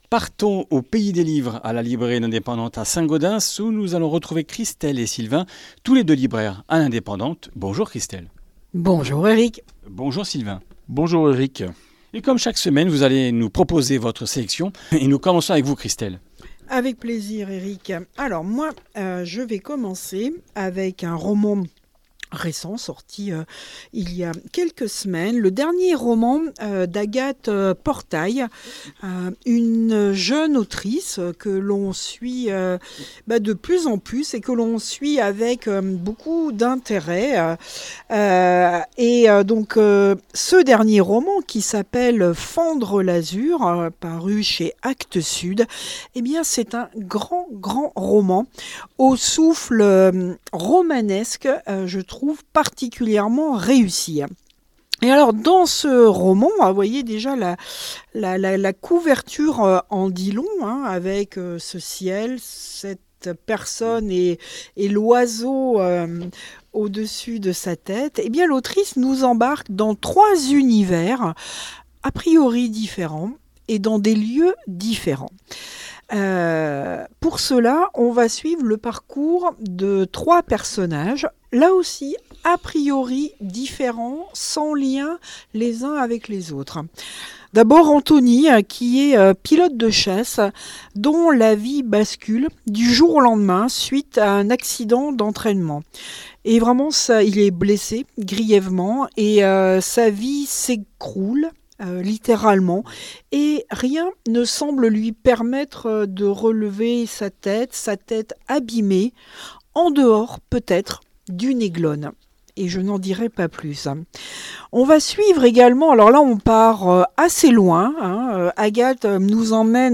Comminges Interviews du 06 juin